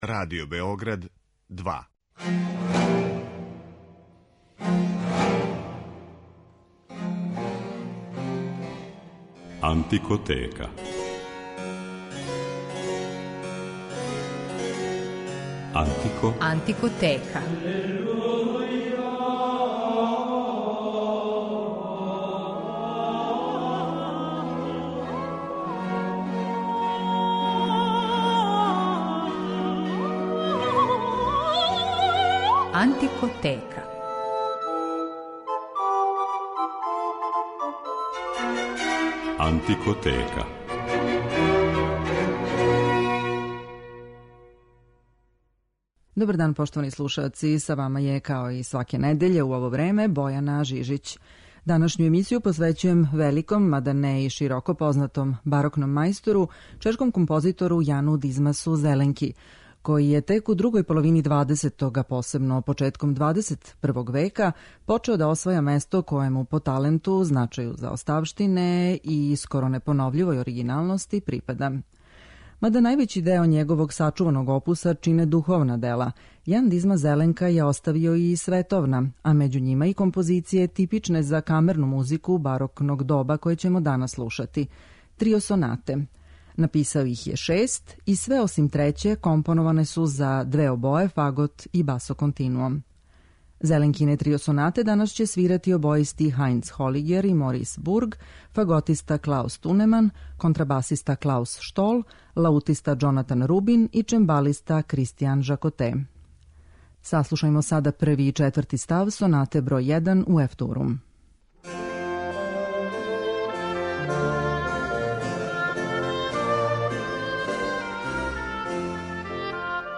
Међу њима су и остварења типична за камерну музику барокног доба, која ћемо данас емитовати - трио сонате.